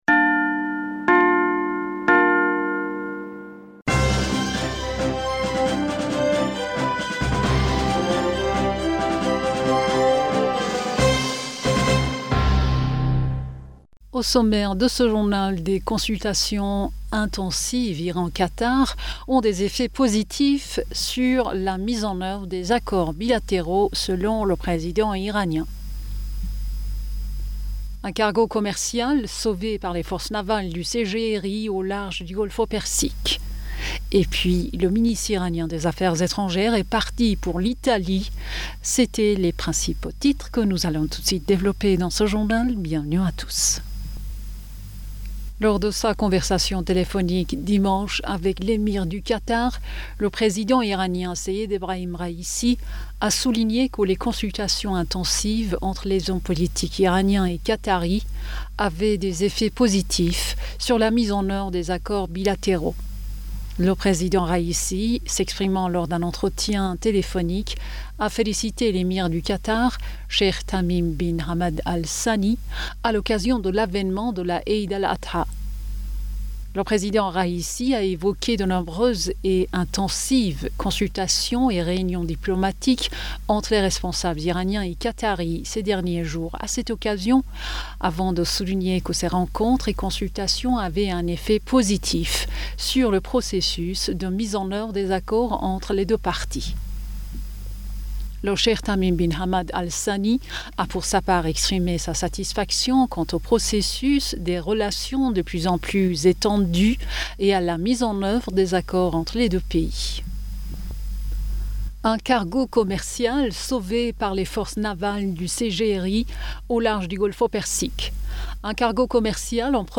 Bulletin d'information Du 11 Julliet